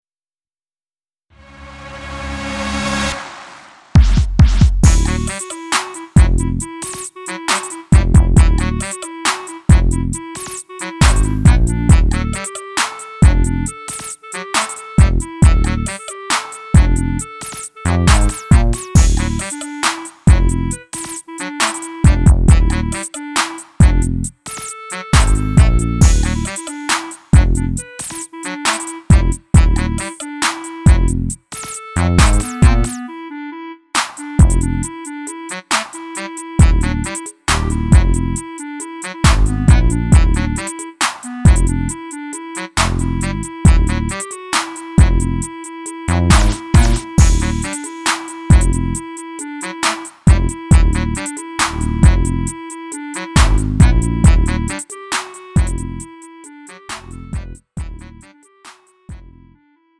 음정 -1키 3:12
장르 구분 Lite MR